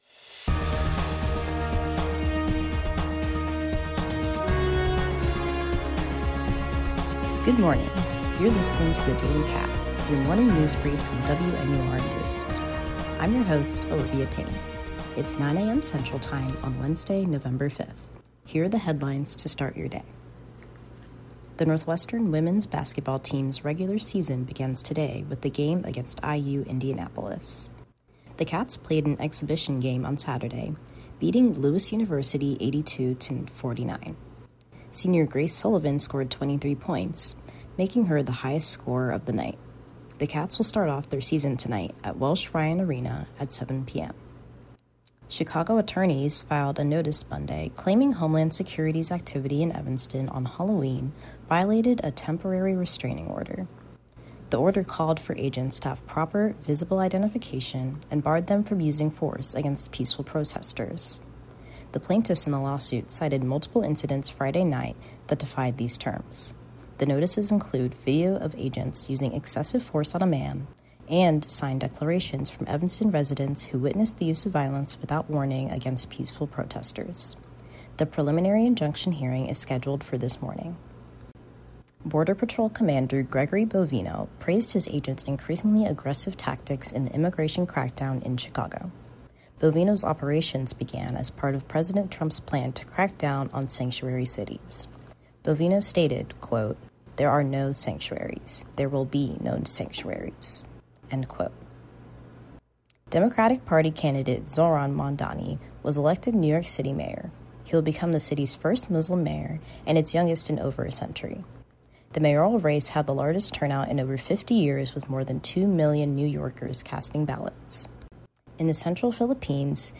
DATE: Women’s Basketball, Homeland Security lawsuit in Evanston, Mamdani elected mayor, Typhoon Kalmaegi. WNUR News broadcasts live at 6 pm CST on Mondays, Wednesdays, and Fridays on WNUR 89.3 FM.